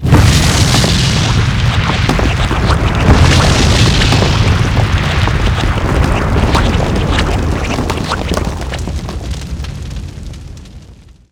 volcano.wav